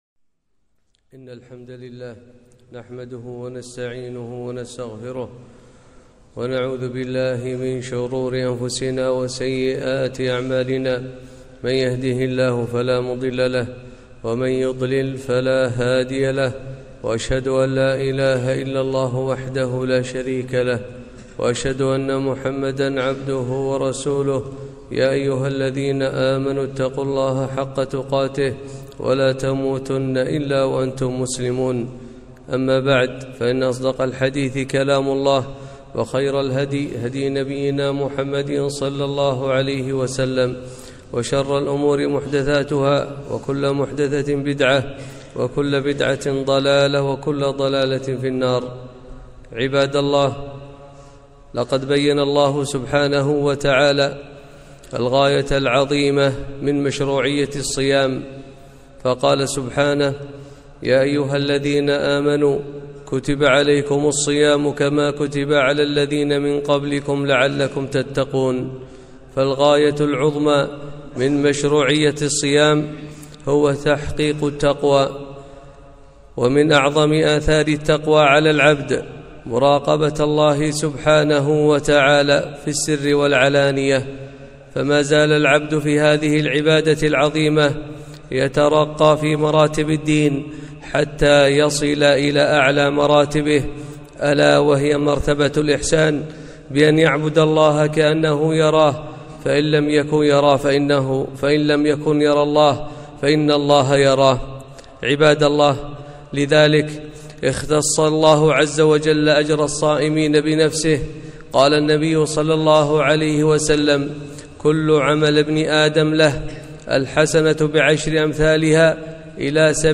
خطبة - مقاصد الصيام